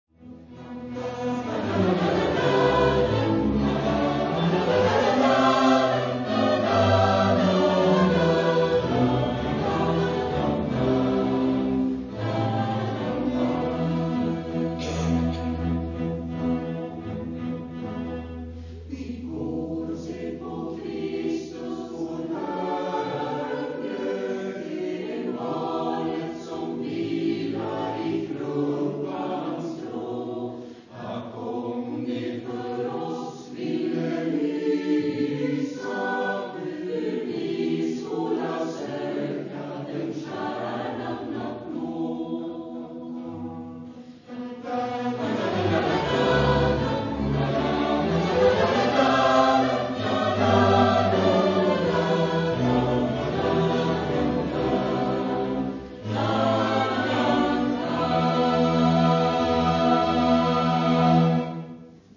Jul i folkviseton
SATB a cappella.
Ovanlig julsång, men den är jättefin, lite folkmusikstuk.
Register: S:B3-B4 A:B3-G4 T:E3-D4 B:A2-C4
Besättning: SATB
Tema/genre/epok: Folkmusik , Jul